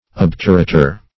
Obturator \Ob"tu*ra`tor\, a. (Anat.)